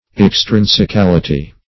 Extrinsicality \Ex*trin`si*cal"i*ty\, Extrinsicalness